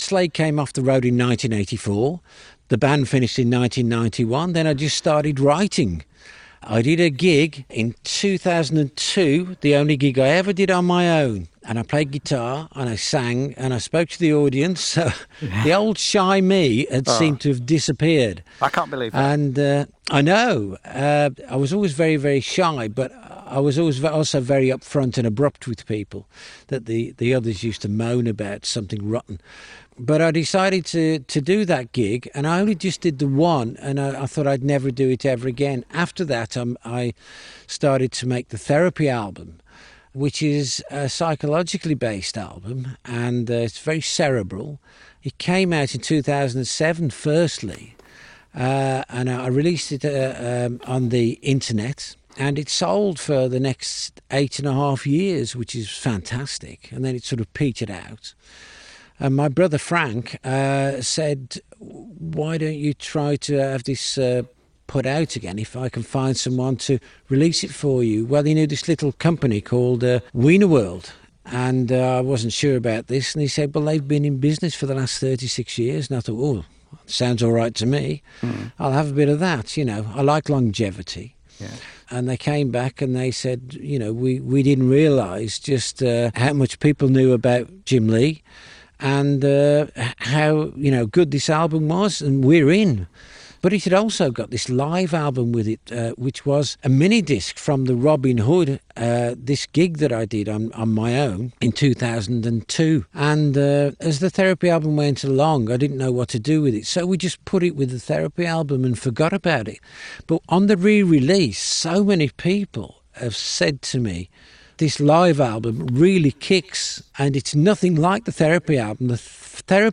Slade legend Jim Lea chats